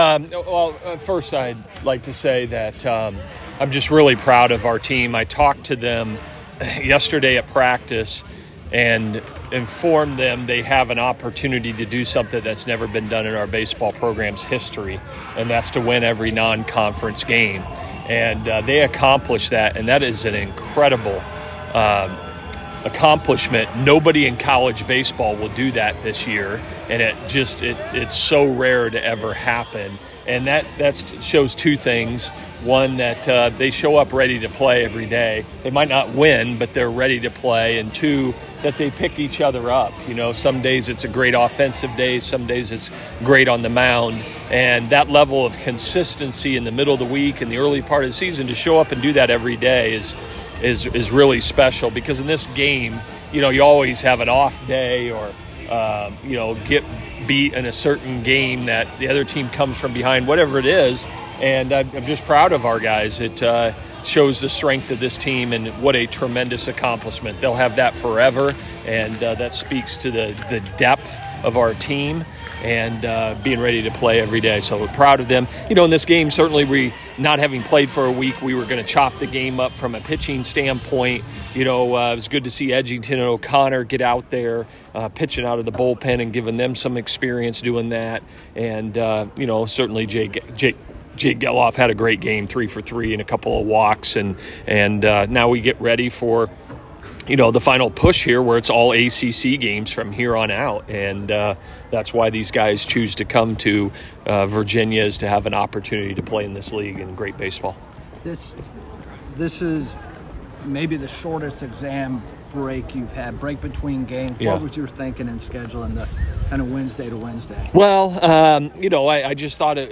bac61db1-oak-postgame-audio-radford.m4a